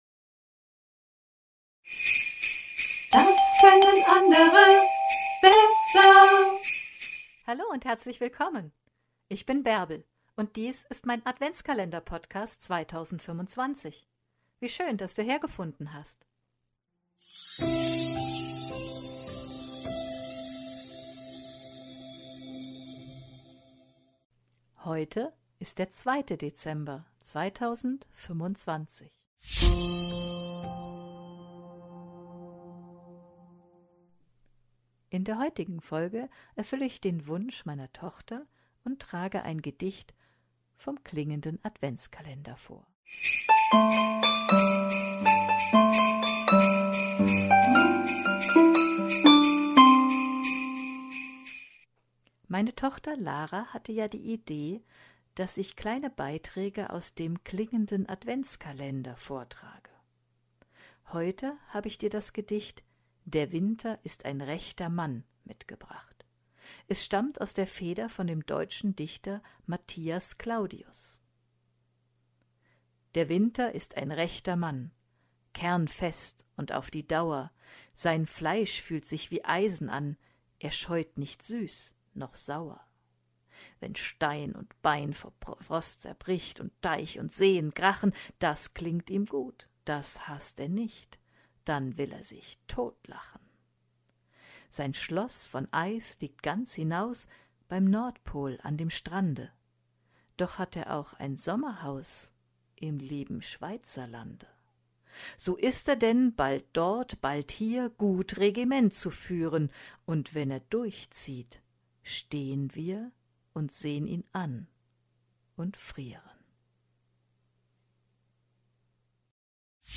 ich erfülle den Wunsch von meiner Tochter und trage ein Gedicht